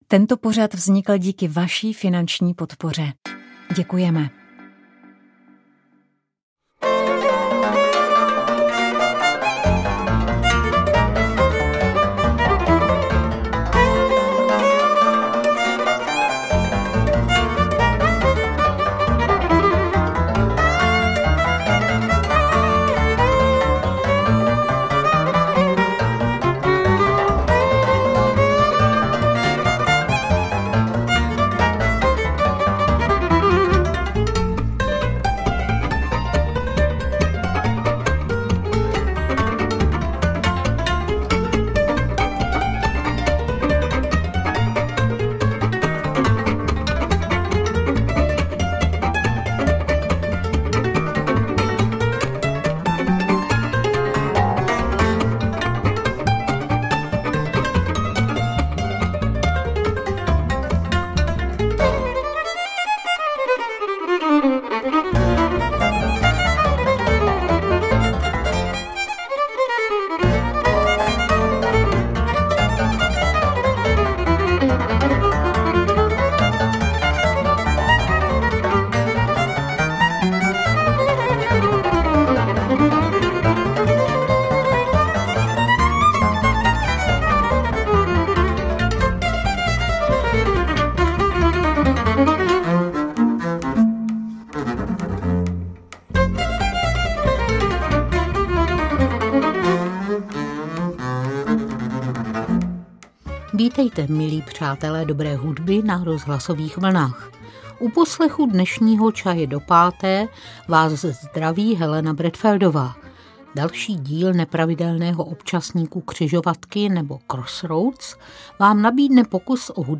Čaj do páté - Křižovatky: Michal Horáček - textař příběhů (rozhovor)